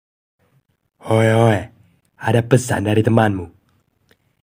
Nada dering pesan WA pendek Oeoe
Kategori: Nada dering